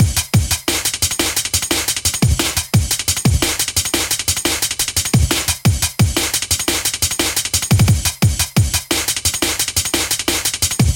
Tag: 175 bpm Drum And Bass Loops Drum Loops 1.85 MB wav Key : Unknown